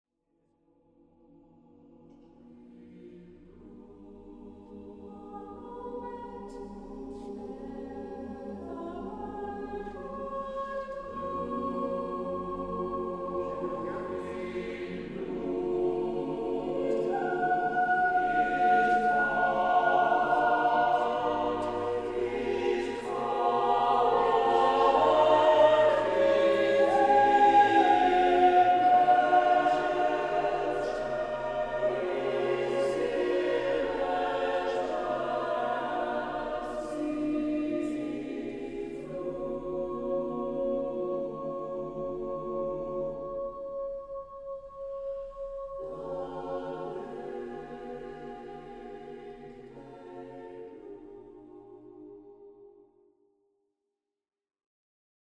Audio excerpts were recorded in concert. Soloists are Consort members.